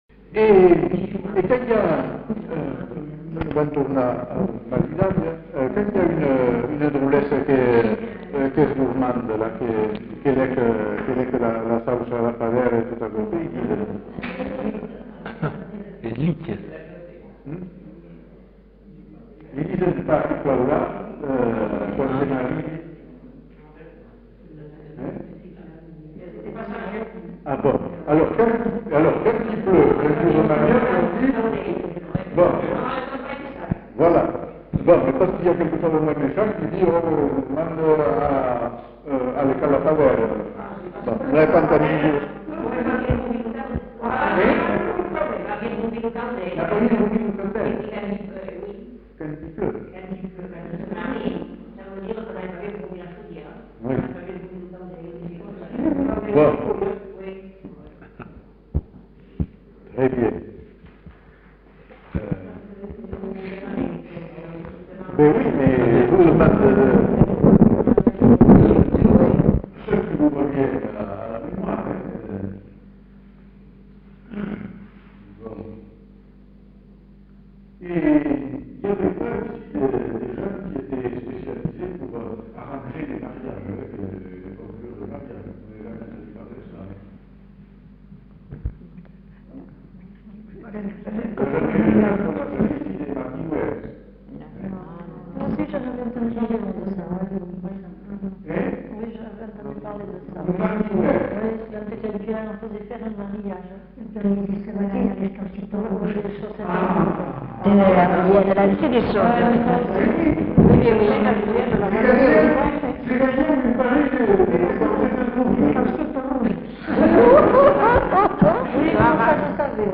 Lieu : Villandraut
Genre : témoignage thématique